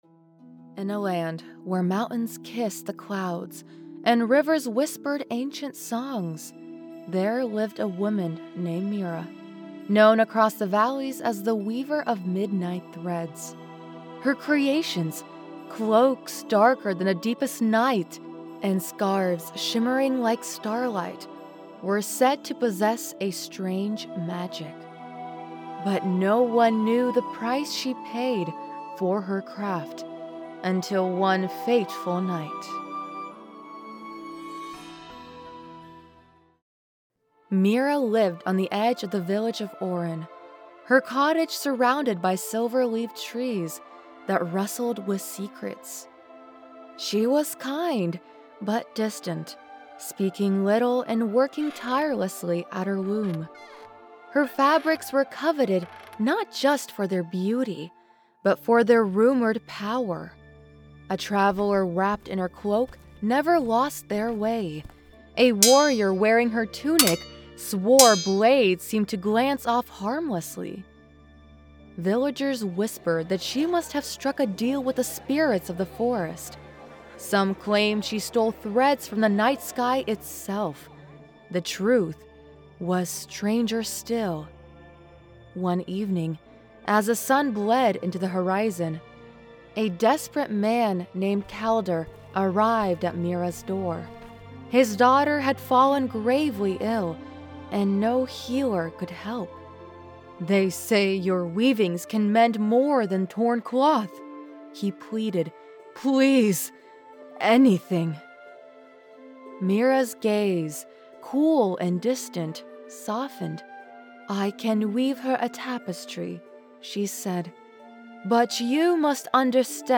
Professionally Treated Vocal Booth
Narration Demo